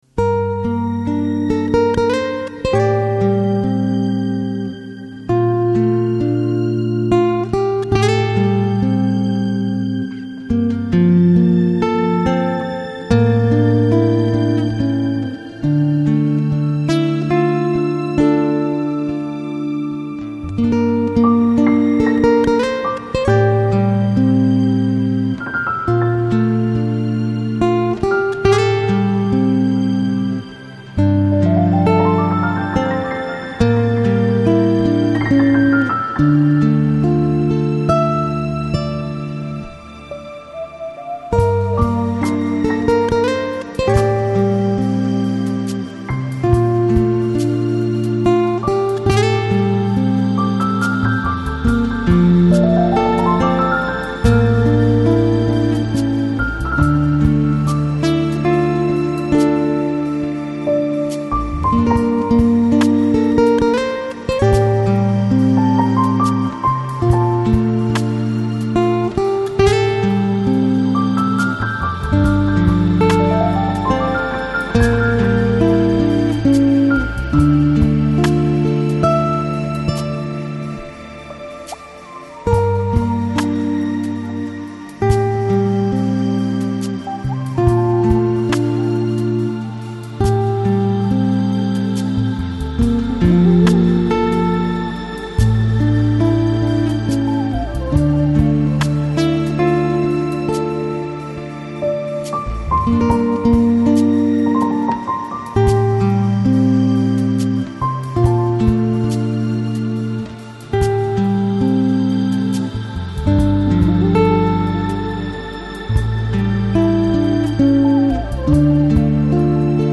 Lounge, Chill Out, Downtempo